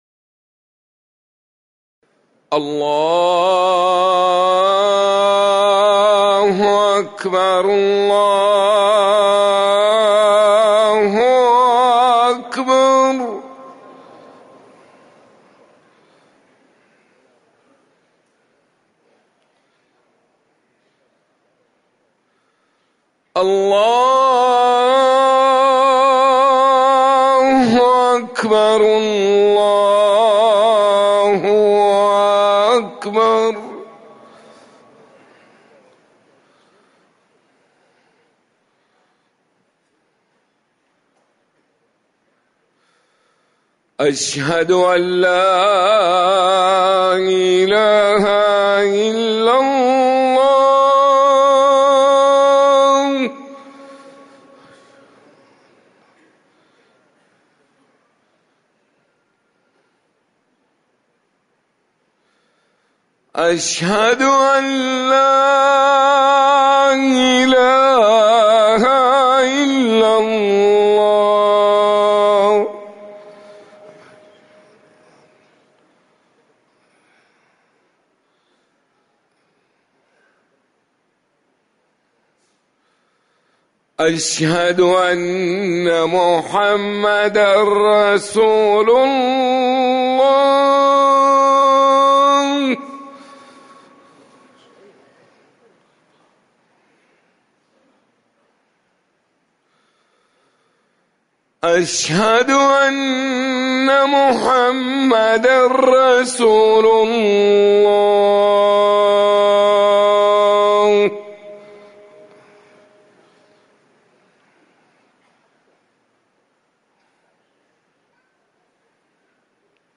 أذان الظهر
تاريخ النشر ٢٢ صفر ١٤٤١ هـ المكان: المسجد النبوي الشيخ